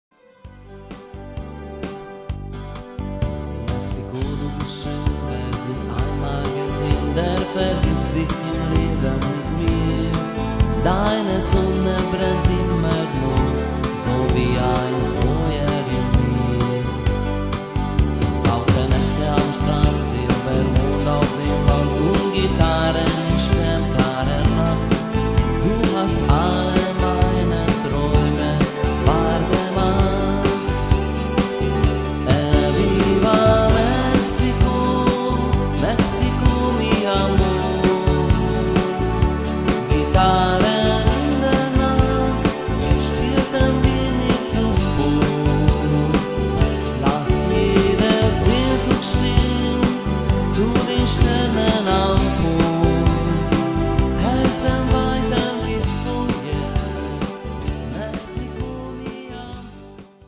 Programm mit meinem Gesang